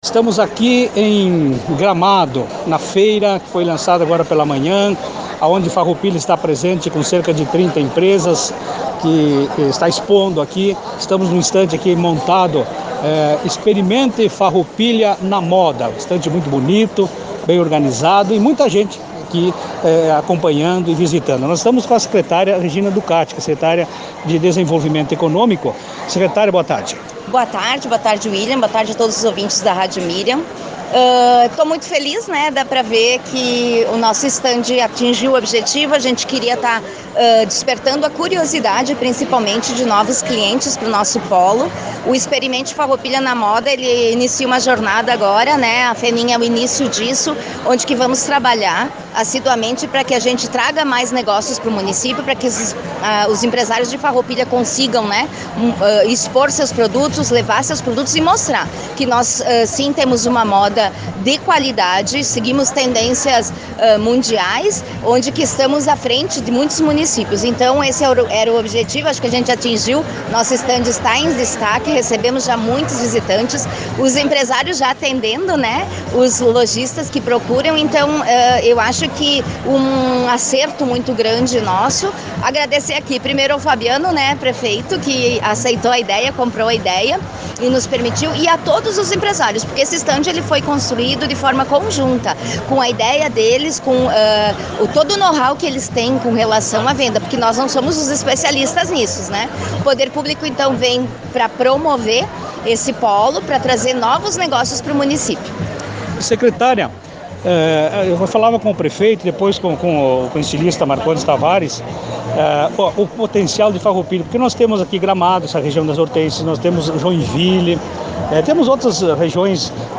Ouça a entrevista completa com a secretária de Desenvolvimento Econômico, Turismo e Inovação de Farroupilha, Regina Celia Ducati: